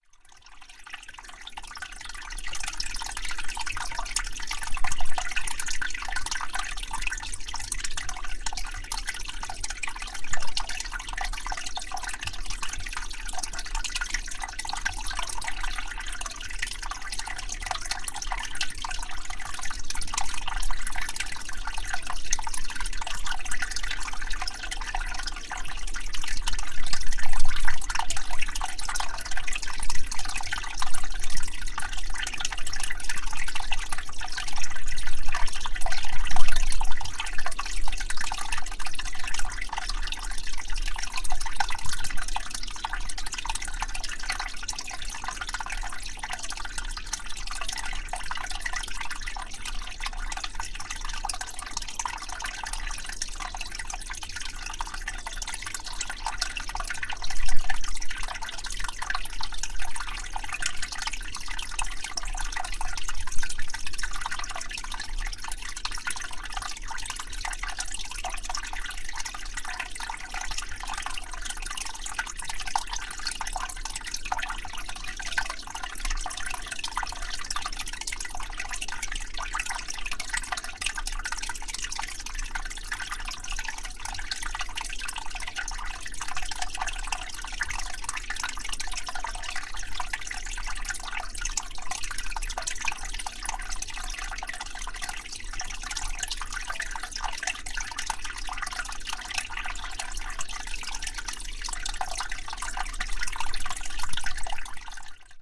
Um fio de água num charco, junto a uma das estradas que dão acesso à aldeia de Carapito, no caso uma estrada municipal que liga à serra da Lapa, concelho de Sernacelhe. Gravado com Fostex FR-2LE e um microfone parabólico Tellinga PRO 7.
Tipo de Prática: Paisagem Sonora Rural
Carapito-Fio-de-água.mp3